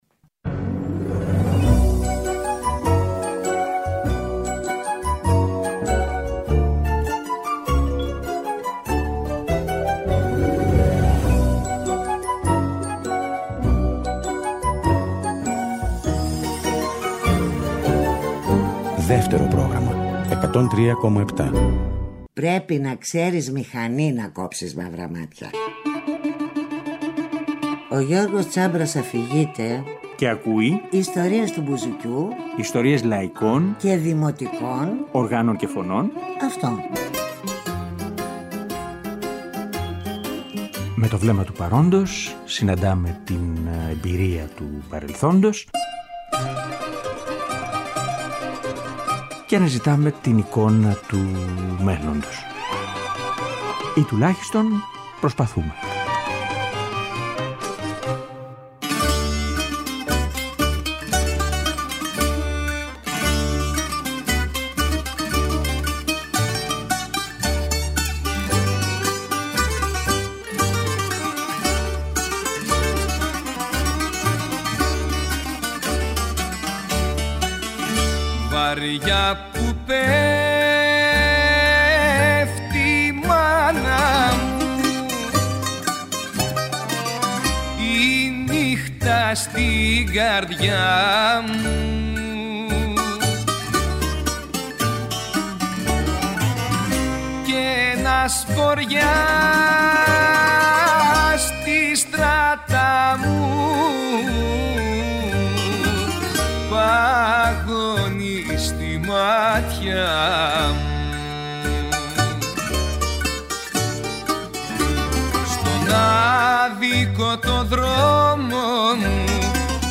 Δύο εκπομπές, δύο εποχές για τη διαχείριση του «ρεμπέτικου» τα τελευταία 40 χρόνια. Στην πρώτη εκπομπή, κομμάτια δισκογραφίας , ζωντανές ηχογραφήσεις σε μικρά μαγαζιά που παίζουν ρεμπέτικα και παλιά λαϊκά τραγούδια, είκοσι χρόνια πριν και …βάλε!